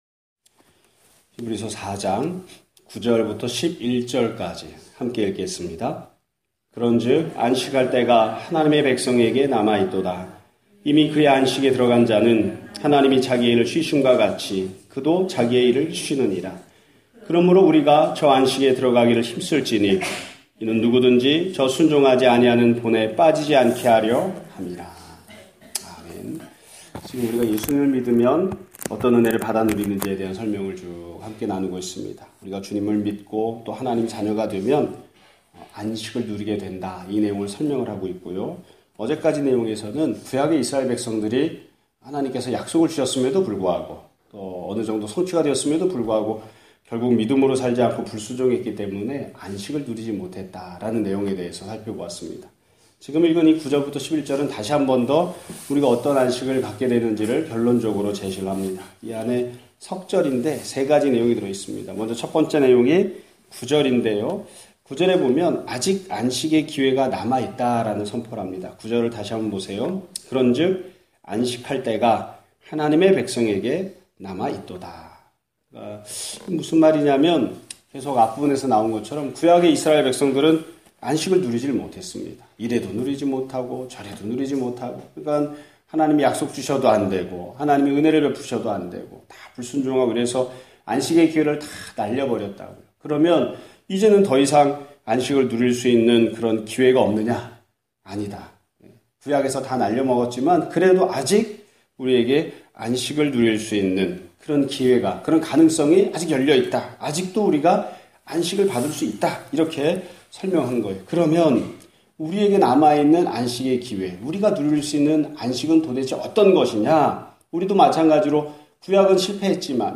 2017년 8월 30일(수요일) <아침예배> 설교입니다.